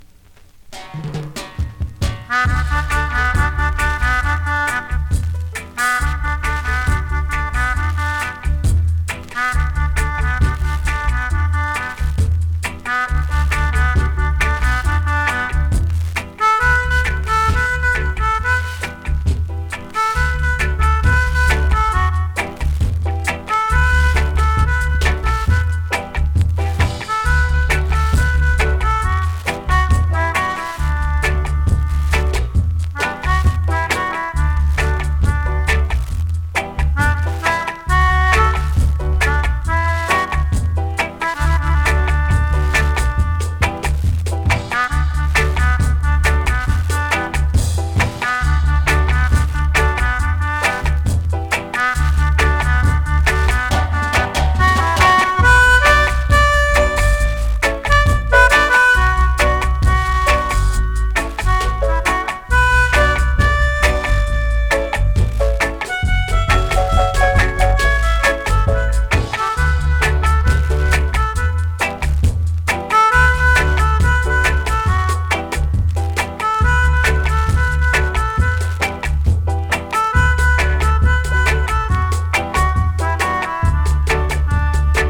2026!! NEW IN!SKA〜REGGAE
スリキズ、ノイズ比較的少なめで